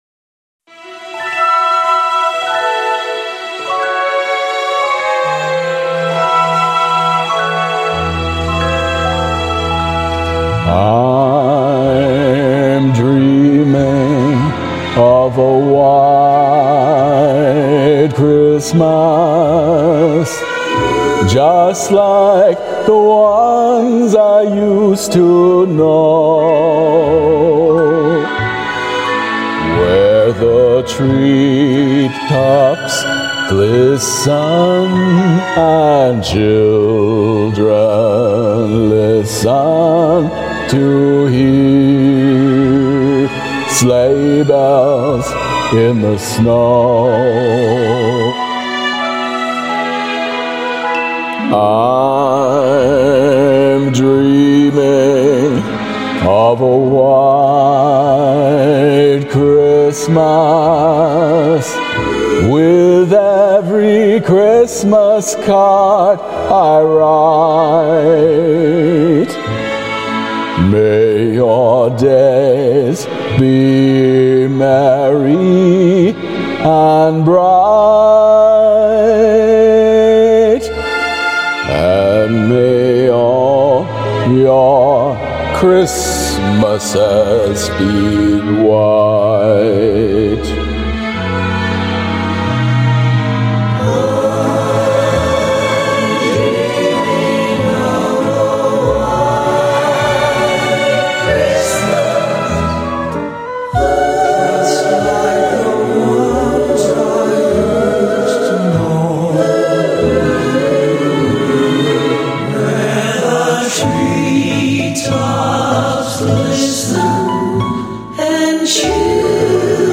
karaoke arrangement: ?